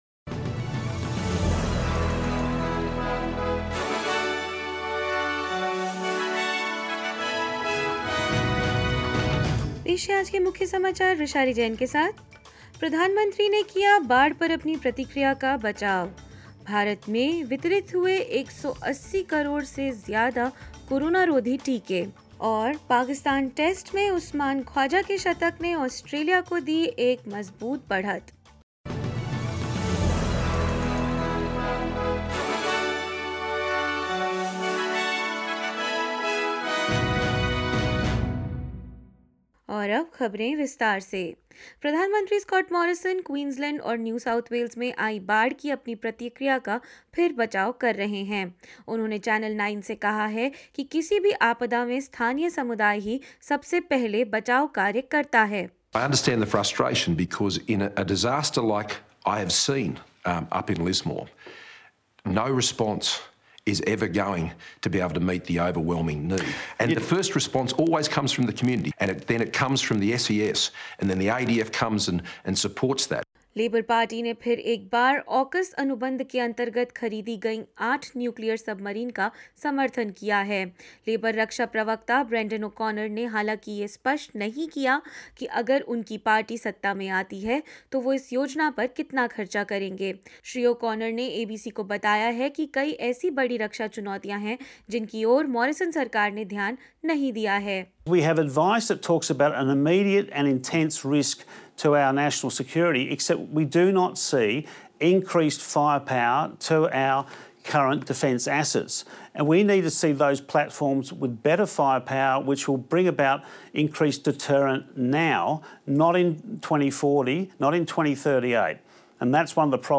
In this latest SBS Hindi bulletin: Prime Minister Scott Morrison has defended his response to floods saying locals are the first responders to any calamity; Israel has offered to run mediation between Ukraine and Russia; Usman Khwaja gives Australia a strong start on the first day of Pakistan Cricket Test match and more news.